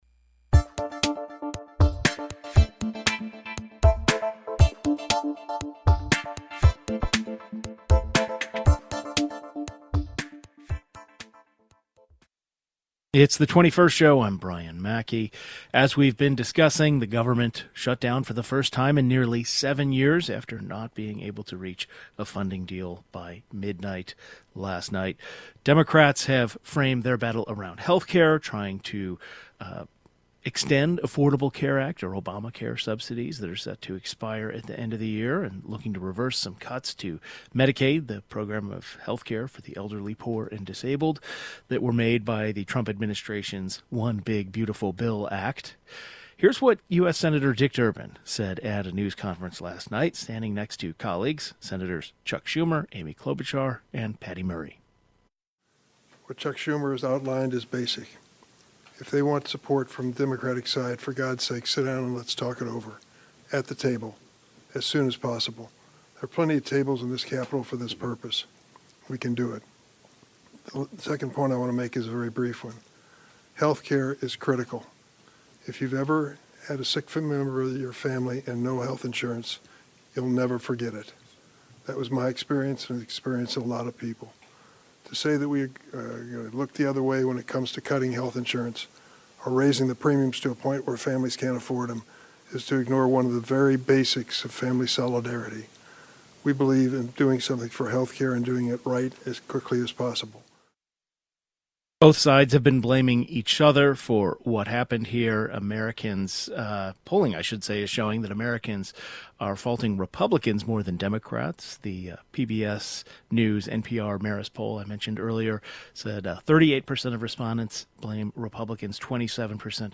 The 21st Show is Illinois' statewide weekday public radio talk show, connecting Illinois and bringing you the news, culture, and stories that matter to the 21st state.
A politcal journalist and a policy expert share their analysis on how this move will impact federal workers in Illinois and the many people who rely on federal benefits.